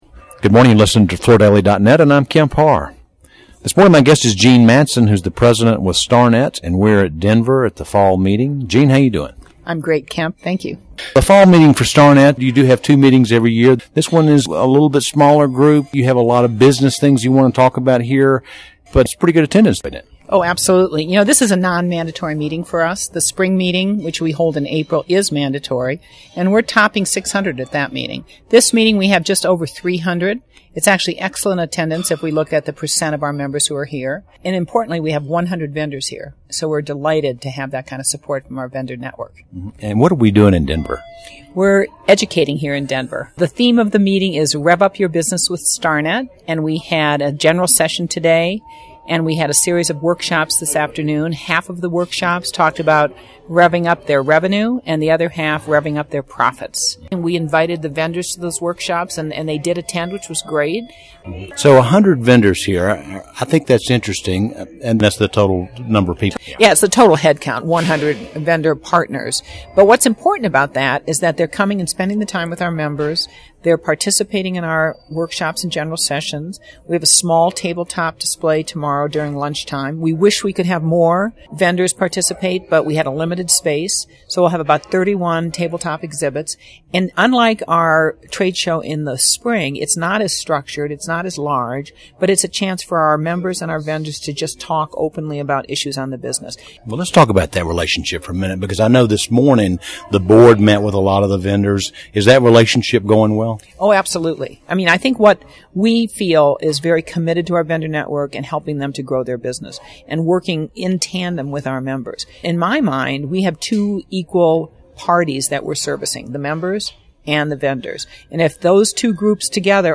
Listen to the interview to hear about business conditions, seminar topics and other details.